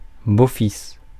Ääntäminen
IPA: [bo.fis]